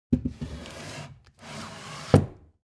SHELVE Open Close Soft 01.wav